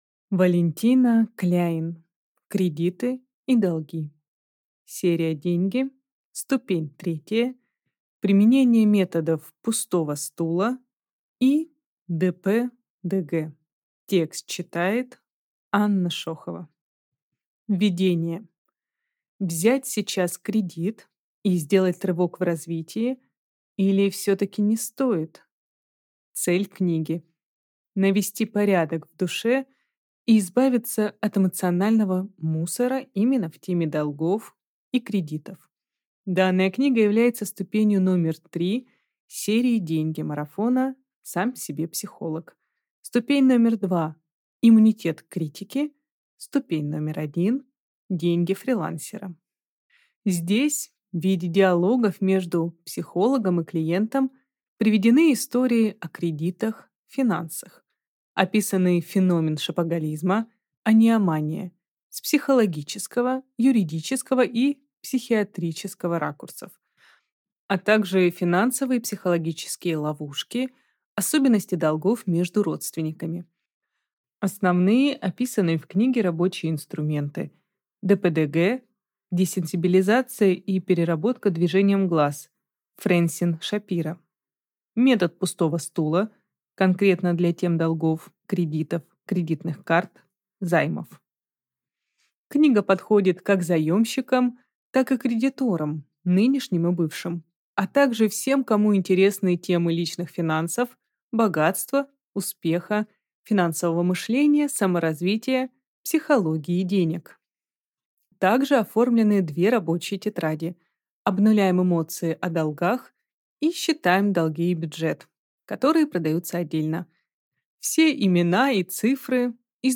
Аудиокнига Кредиты и долги.